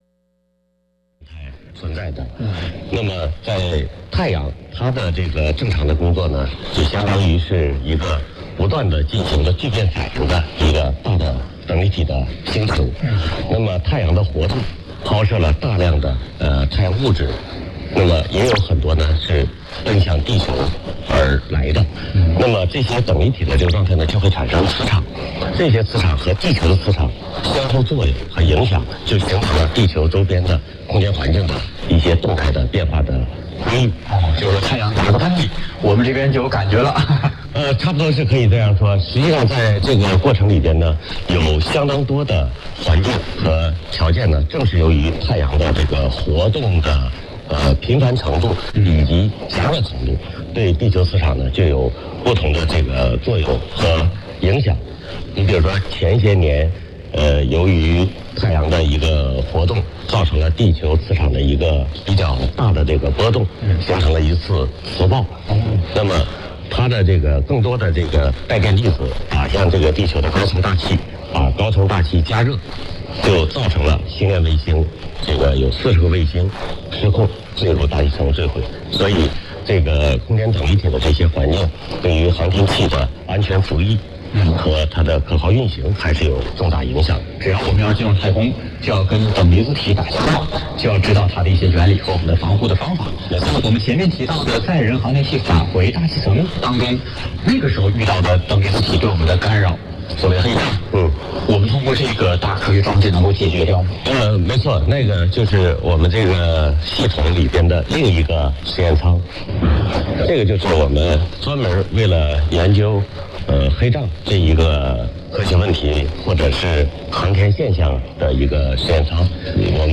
LOC: TAIWAN, MIAOLI, 24.23 N 120.42 E